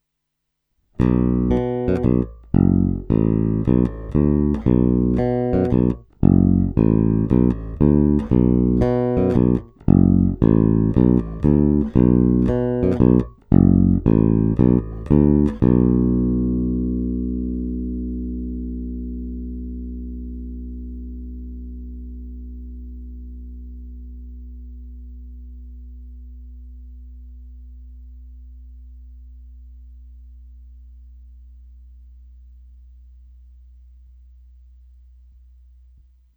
Zvuk je variabilní, plný, čitelný, má ty správné středy, díky kterým se prosadí v kapelním zvuk a taky jej pěkně tmelí.
Oba snímače